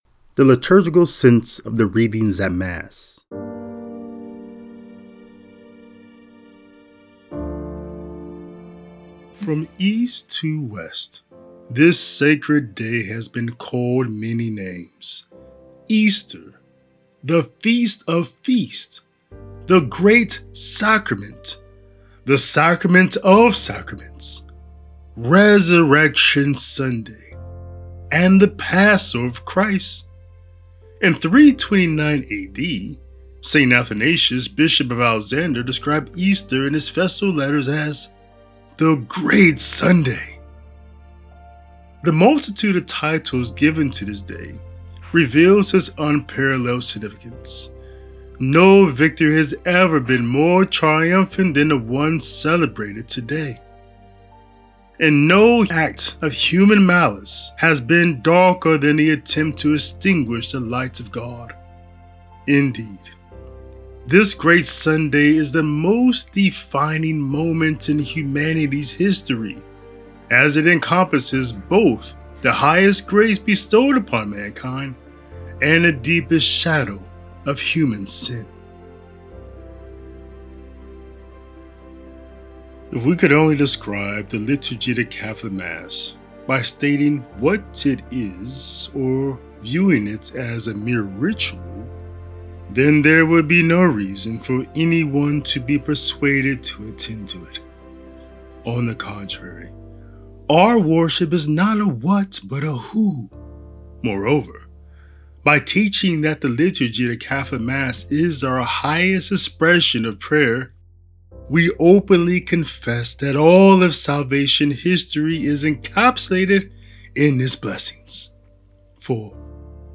A Commentary and Reflection on the Readings for the Easter Vigil and Easter Sunday.